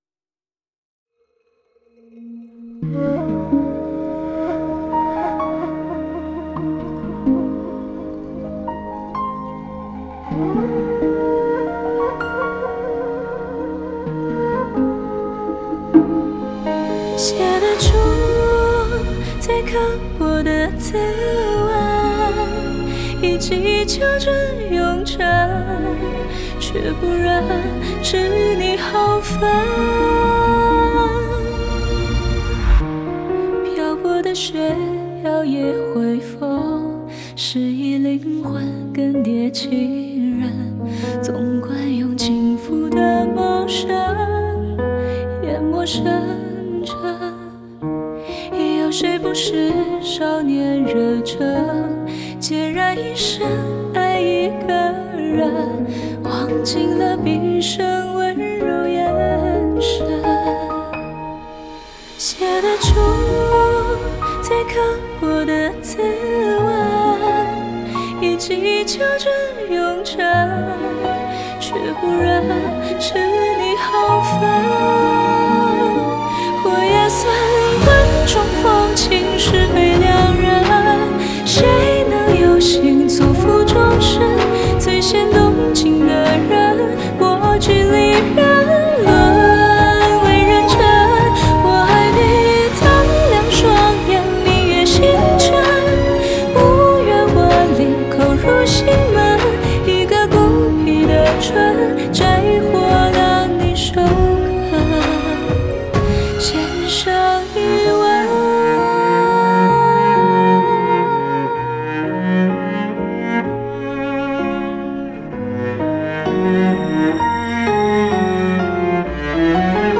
Ps：在线试听为压缩音质节选，体验无损音质请下载完整版
吉他
贝斯
竹笛
和声
弦乐
大提琴